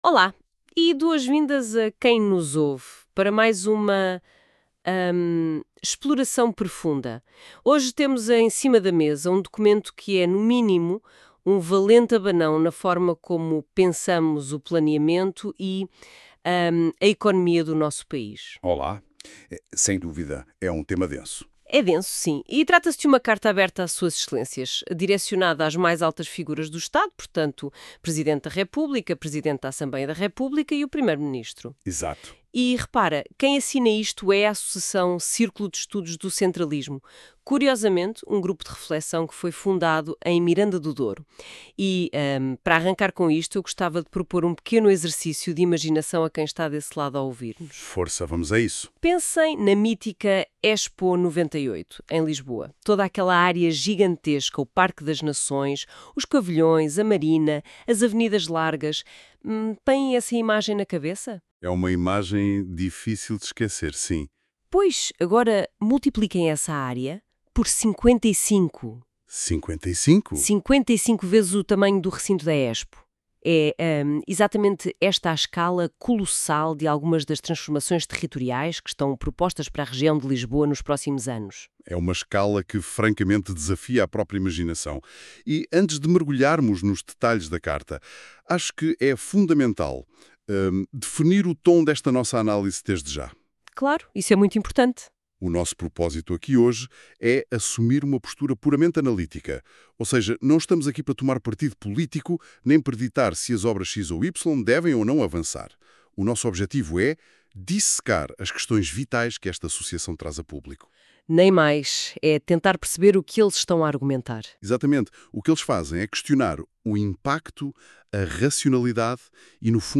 Carta Aberta aos Órgãos de Soberania inspira novo Podcast gerado por IA